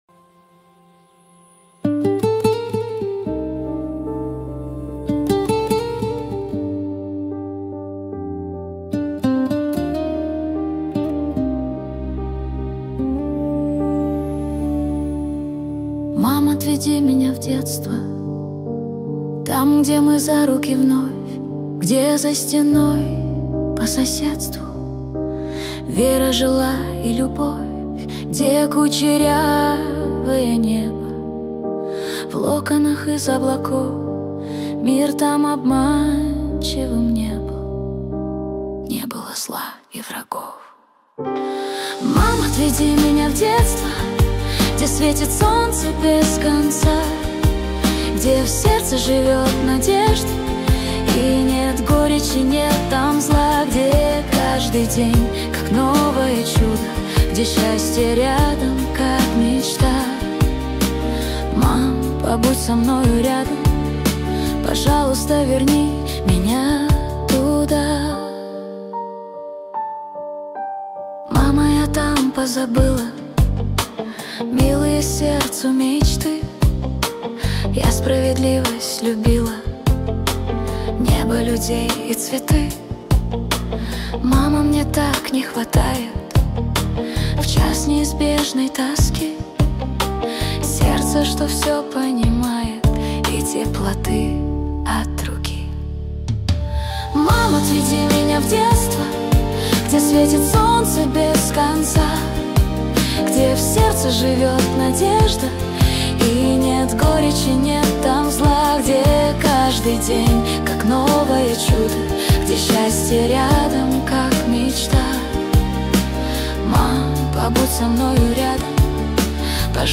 13 декабрь 2025 Русская AI музыка 92 прослушиваний